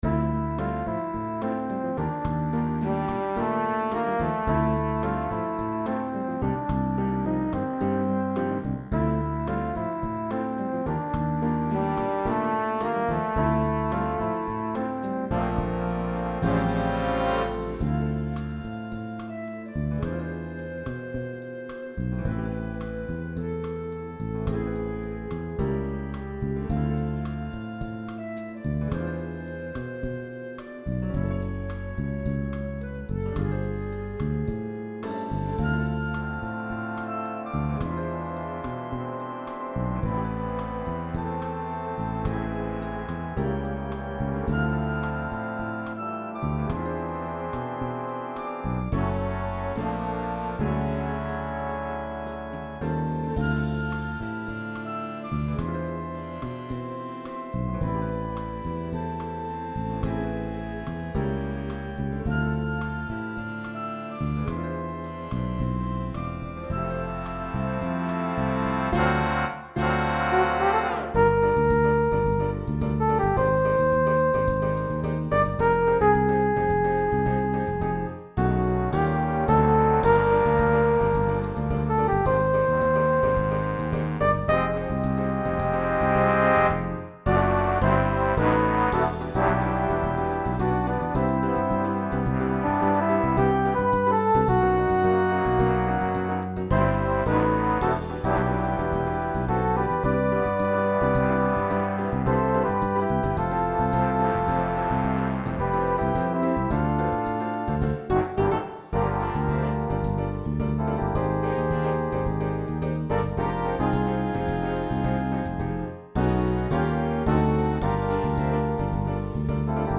Big Band
Bossa Nova & Samba